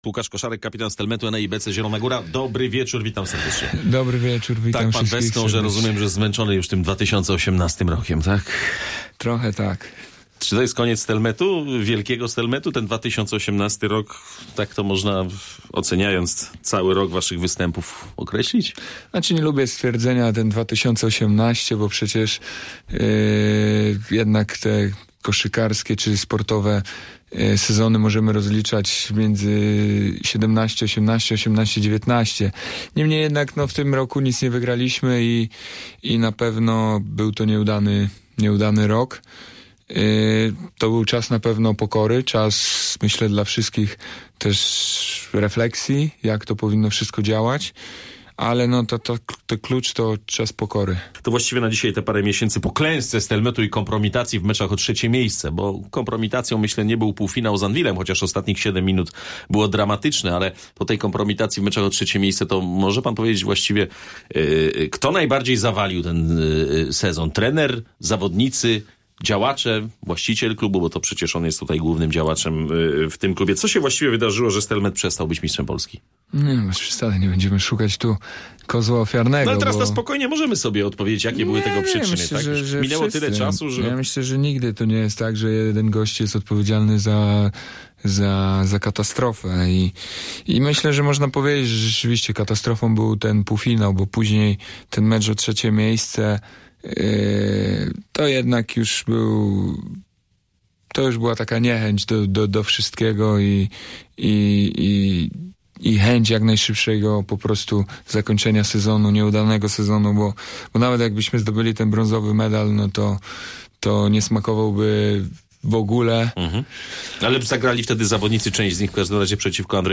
Kapitan Stelmetu ENEI BC Zielona Góra Łukasz Koszarek był gościem programu Muzyka i Sport.